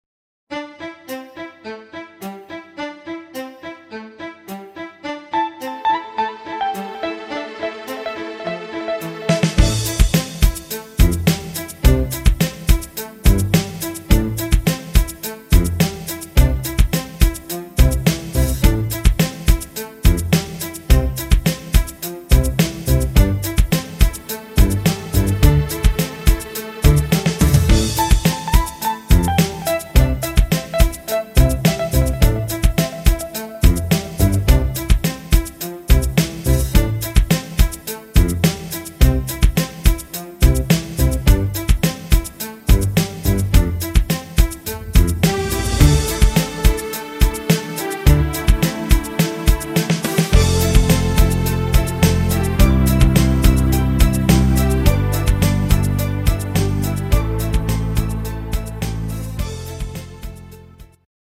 Rhythmus  Pop Rap
Art  Deutsch, Pop